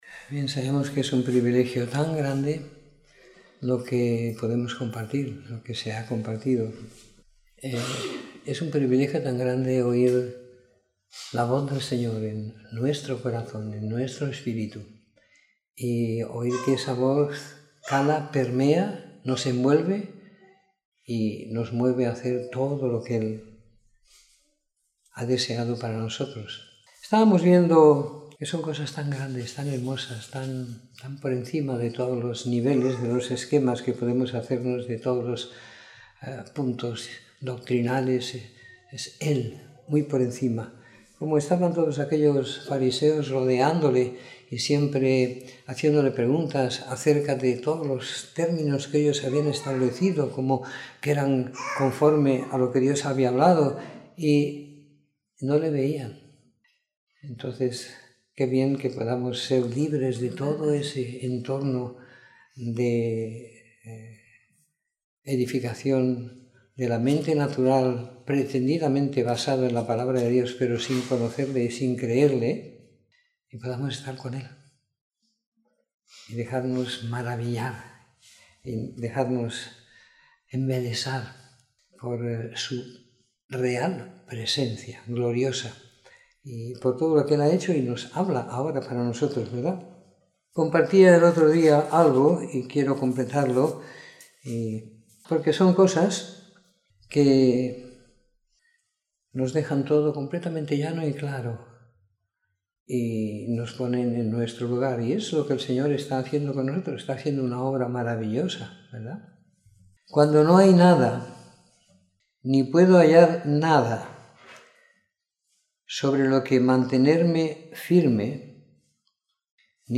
Domingo por la Tarde . 03 de Diciembre de 2017